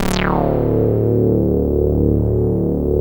26-SAWRESWET.wav